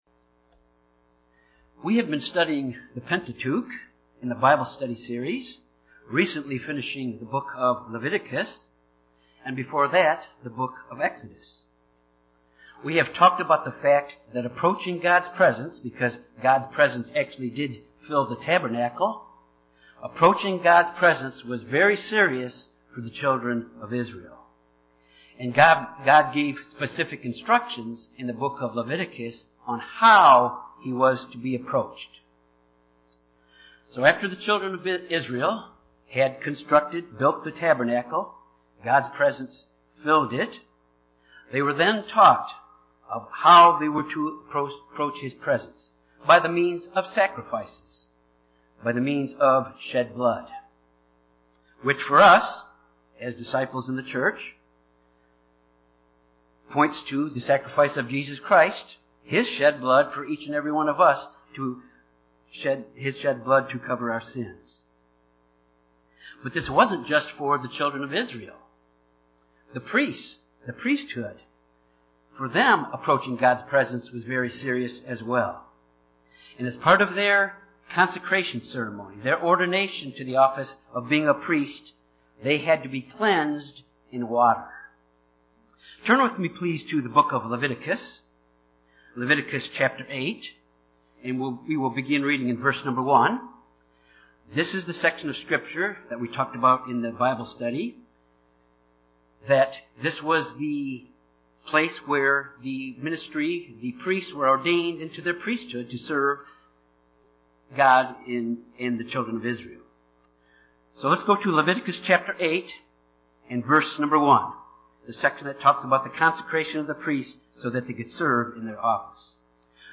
Sermons
Given in Jonesboro, AR Little Rock, AR